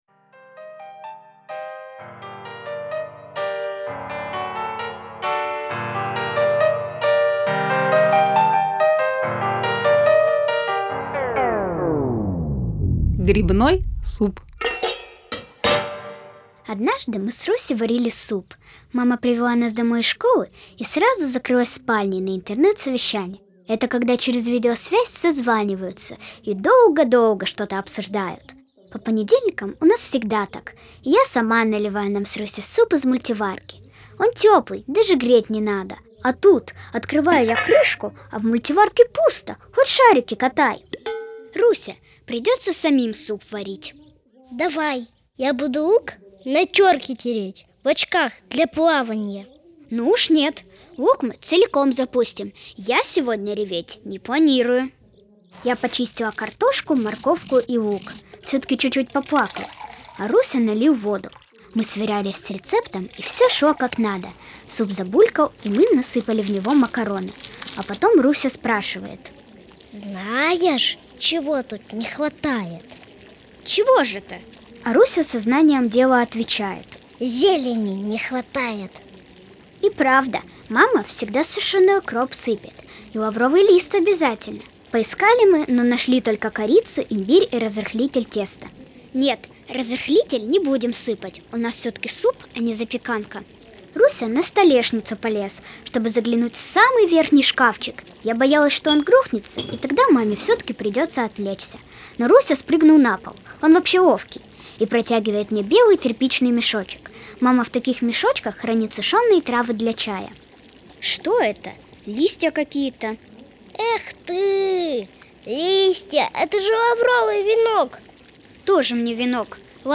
Грибной суп - аудио рассказ Артемкиной - слушать онлайн
Фортепианная партия